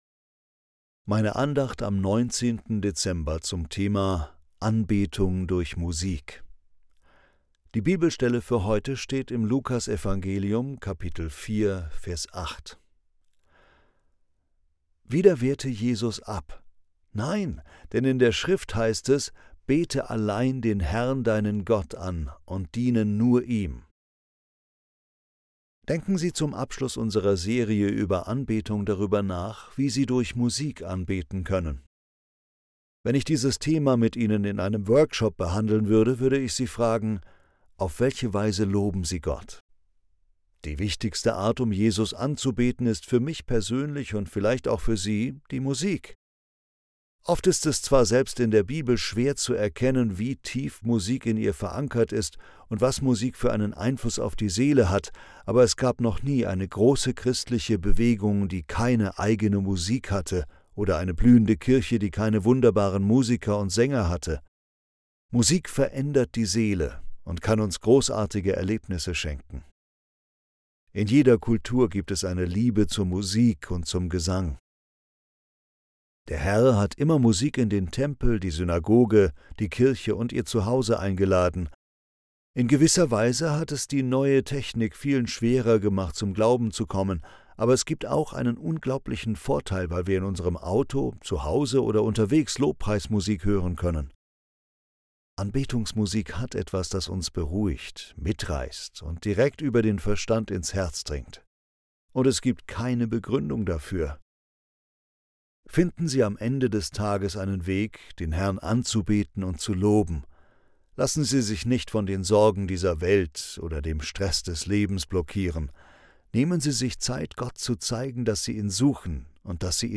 Andacht zum 19. Dezember